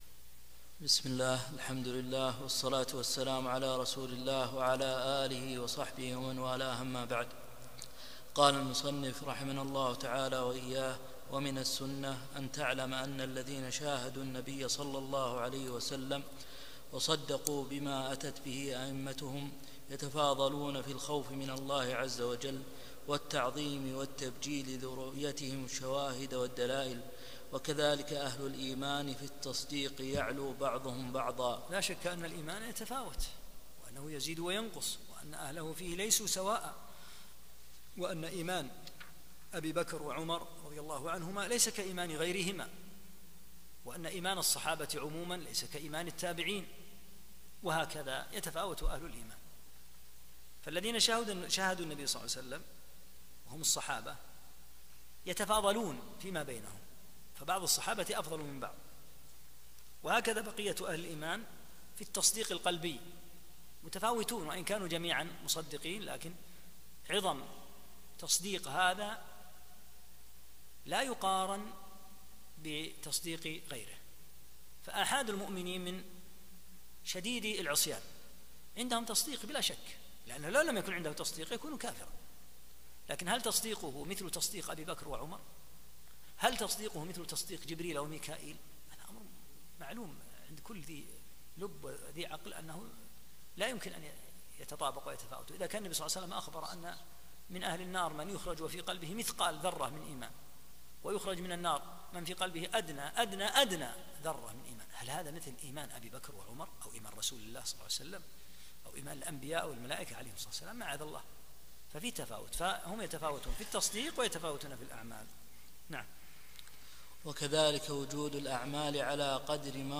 46- الدرس السادس والأربعون